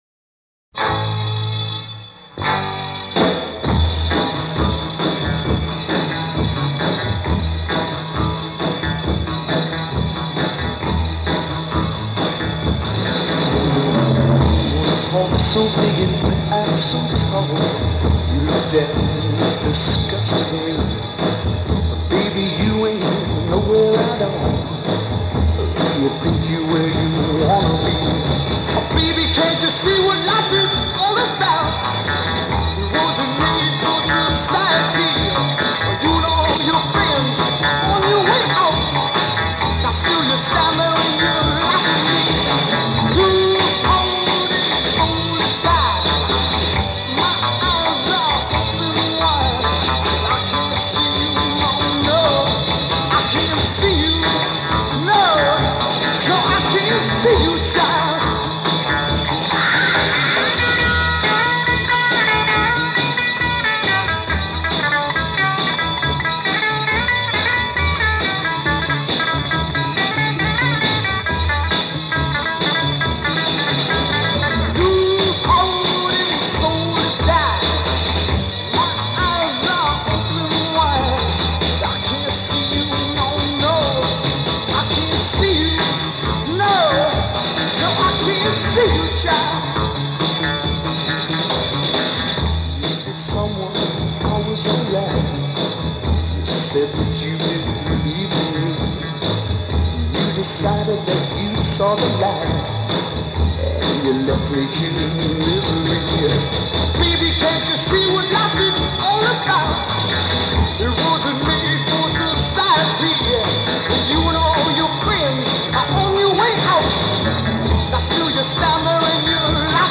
great garage rockers